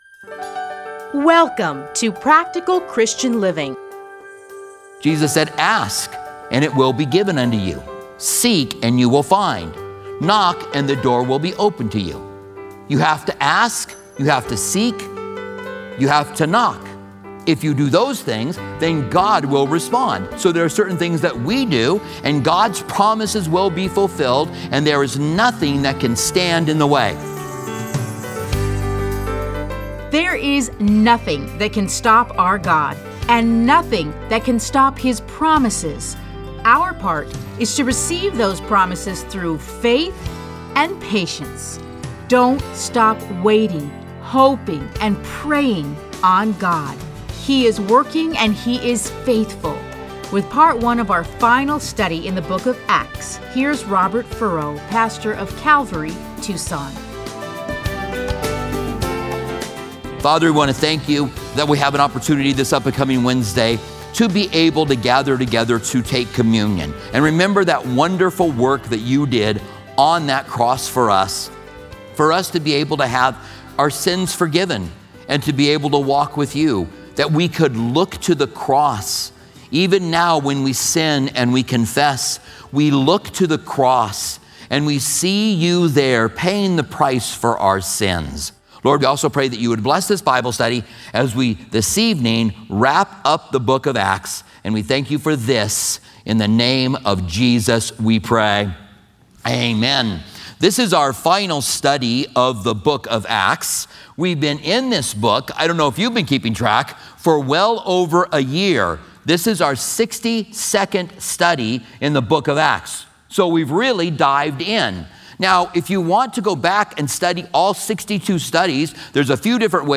Listen to a teaching from Acts 28:1-31.